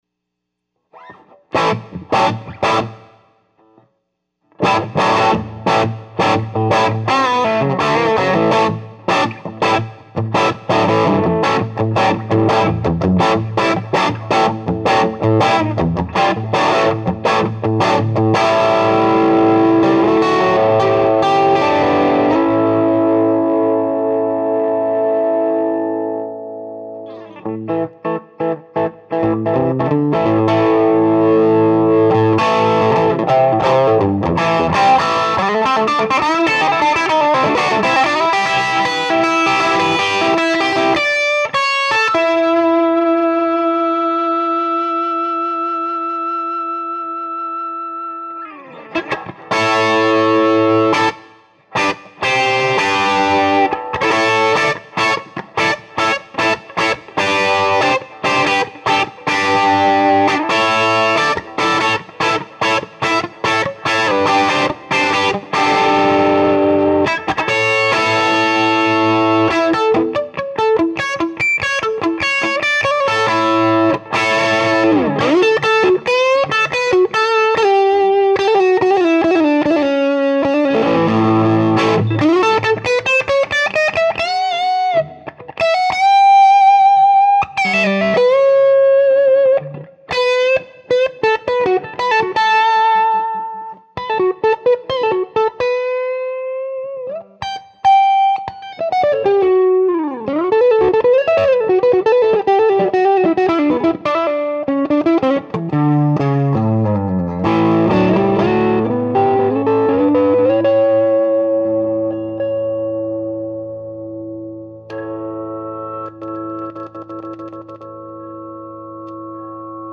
6v6_LesPaul.mp3